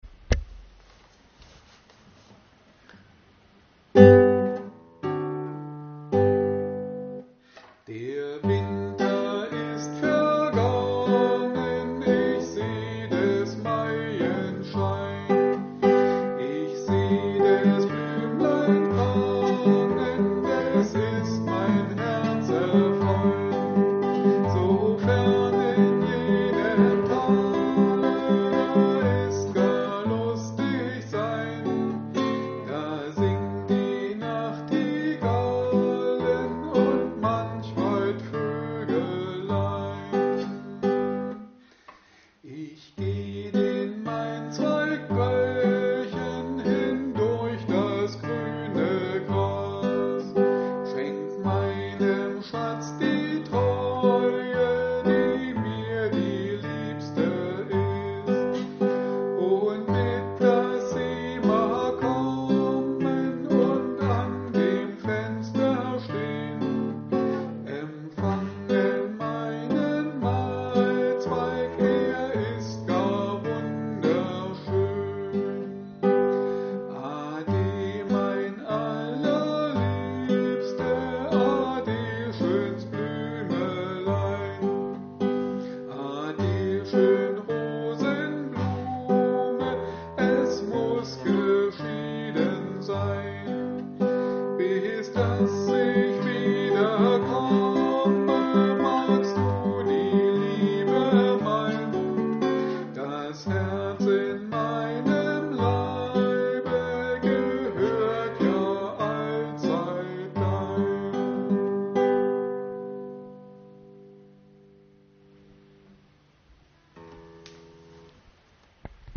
Da auch wir zur Zeit in vielen Seniorenheimen nicht arbeiten können und folglich auch keine Gruppen stattfinden, haben wir Ihnen ein kleines Liederbuch mit dazugehöriger Begleitung zusammengestellt.